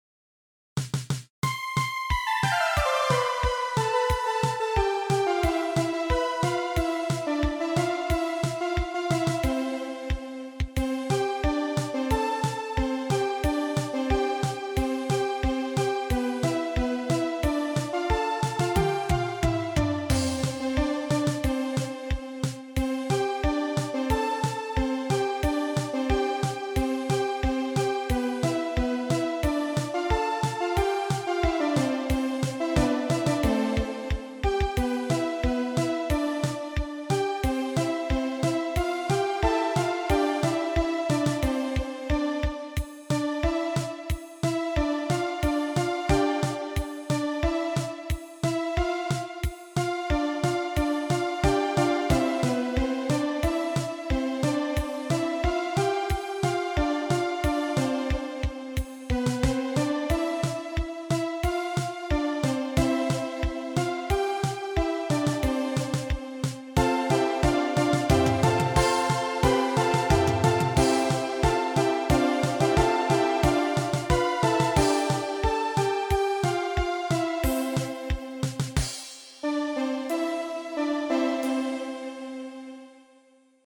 C21-001 is first melody I have written for quite a long time.